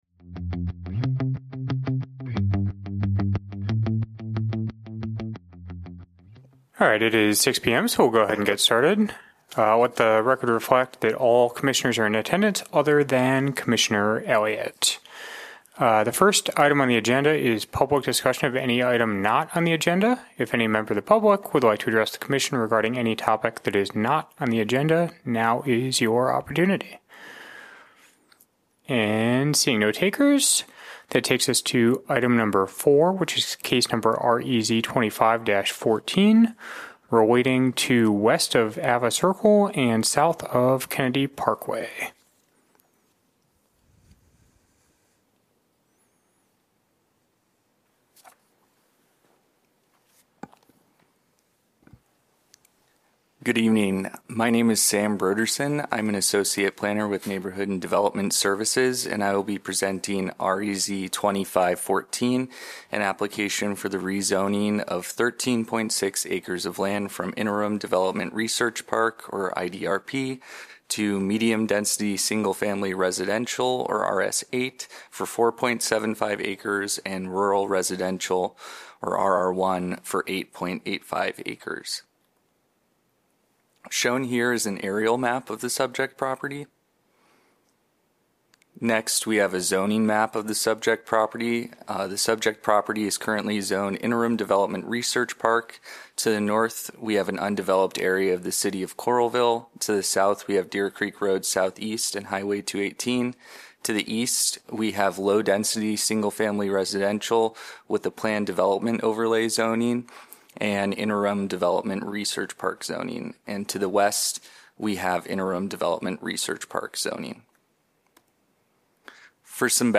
Regular semi-monthly meeting of the Planning and Zoning Commission.